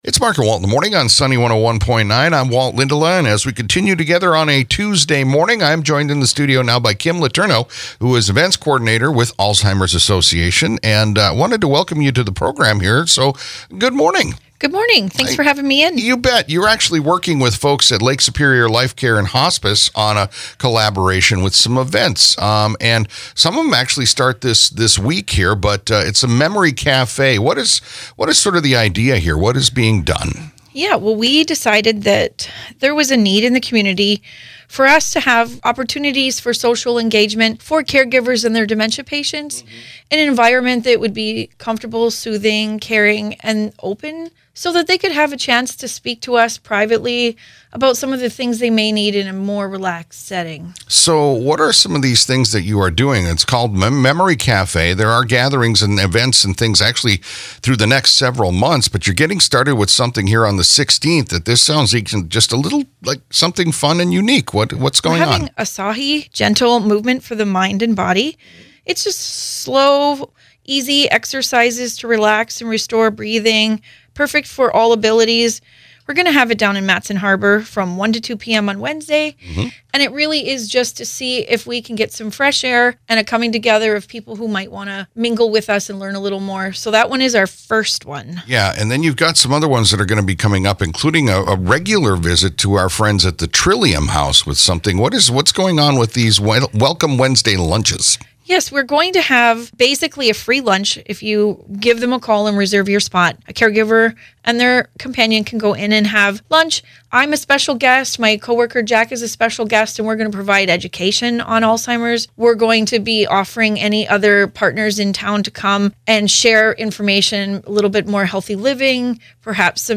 spoke about the events